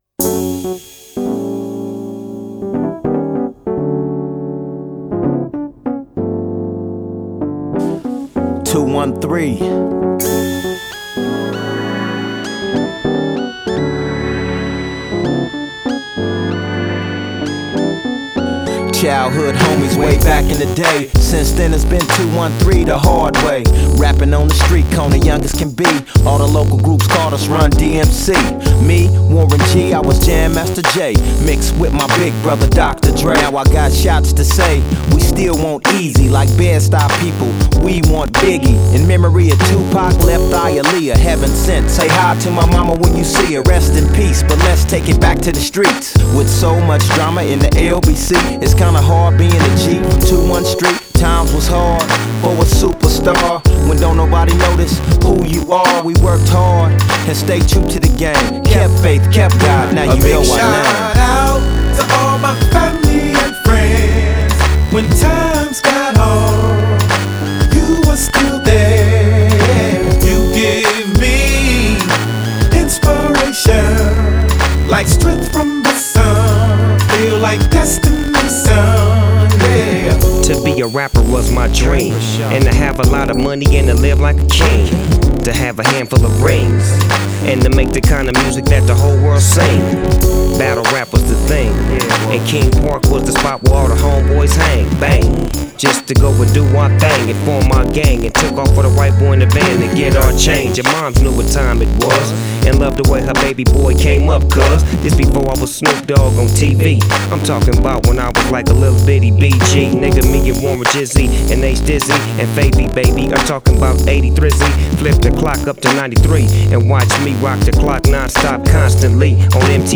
G-funkは今でも生き続けています。